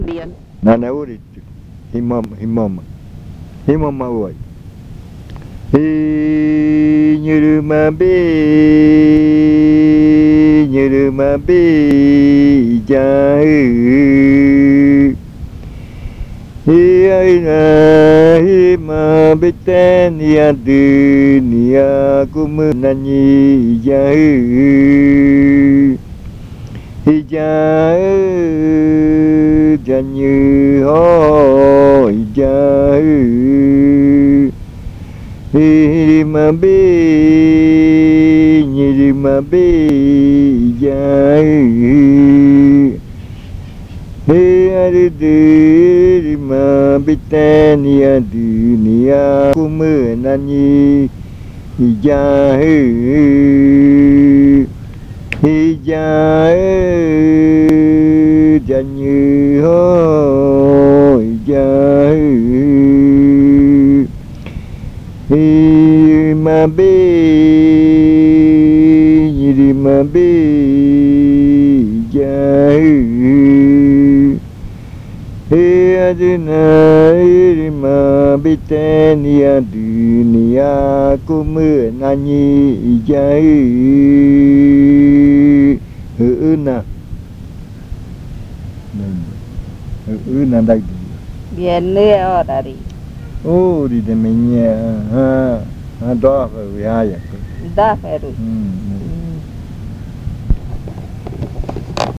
Leticia, Amazonas
Se canta en el día y también en la noche. Uuriya rua, traducción de canto uuriya jirima bii en otros tonos de voces.
Uuriya rua, translation of uuriya jirima bii singing in other voice tones.